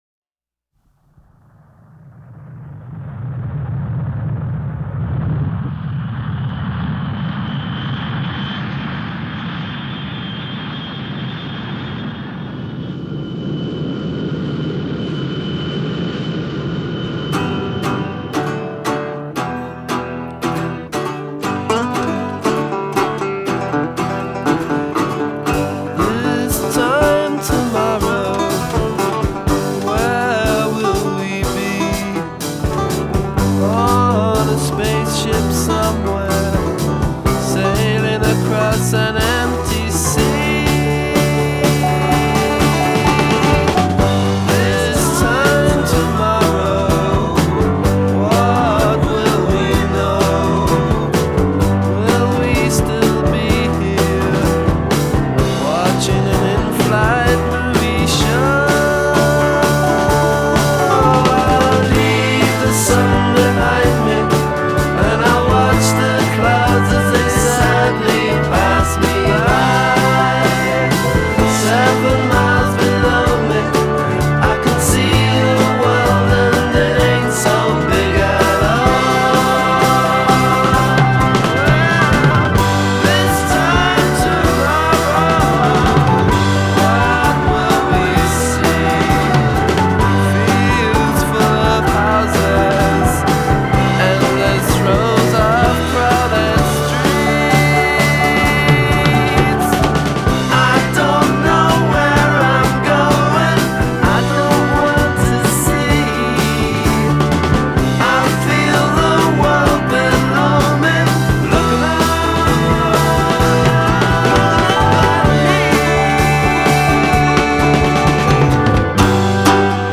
punk rock before there was punk rock